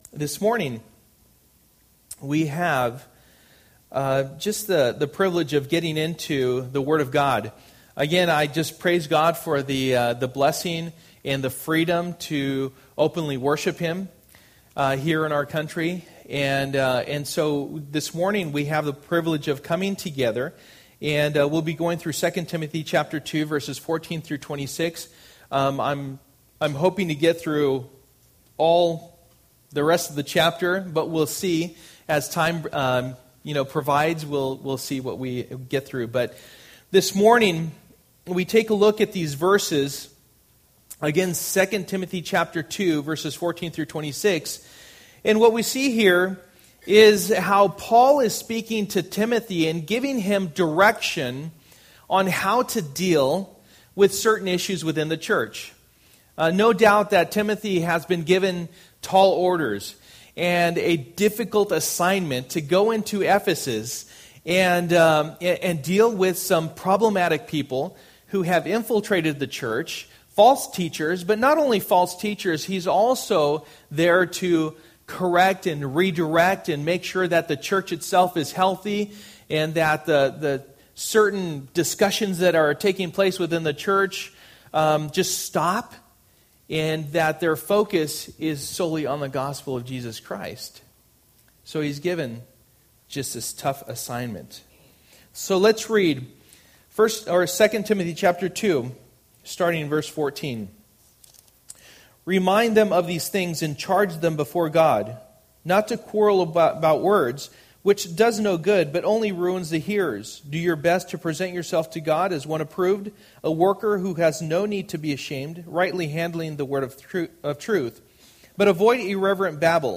Passage: 2 Timothy 2:14-26 Service: Sunday Morning